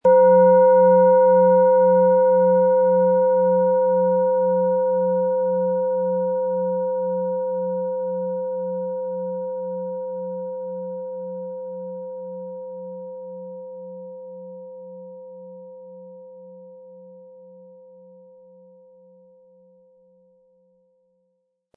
Planetenton 1
Unter dem Artikel-Bild finden Sie den Original-Klang dieser Schale im Audio-Player - Jetzt reinhören.
Durch die überlieferte Fertigung hat diese Schale vielmehr diesen außergewöhnlichen Ton und die intensive Berührung der mit Liebe hergestellten Handarbeit.
Mit Klöppel, den Sie umsonst erhalten, er lässt die Planeten-Klangschale Jupiter voll und harmonisch erklingen.
MaterialBronze